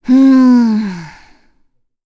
Cecilia-Vox_Think_kr.wav